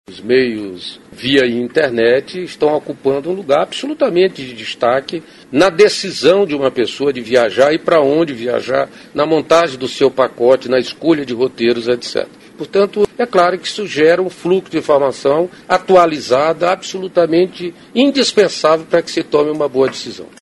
aqui para ouvir declaração do ministro Gastão Vieira sobre a crescente utilização da internet na montagem de roteiros de viagem.